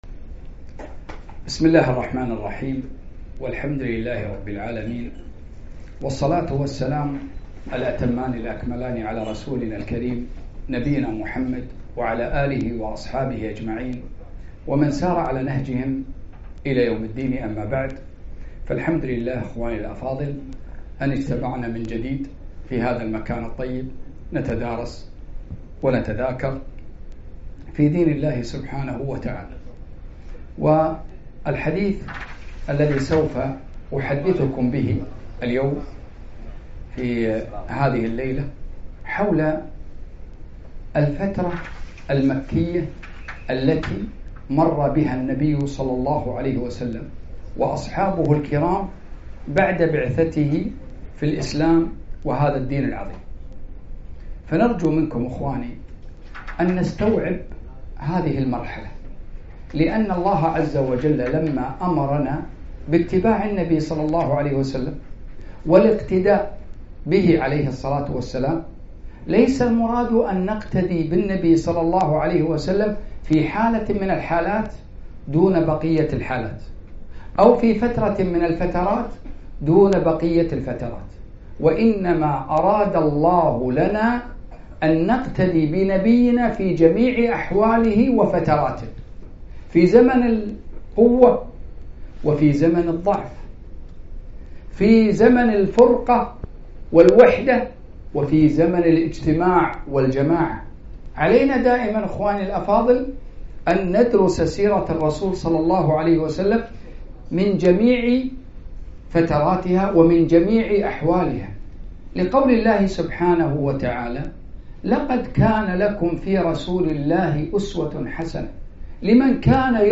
محاضرة حال الرسول ﷺ في مكة وحال المسلمين اليوم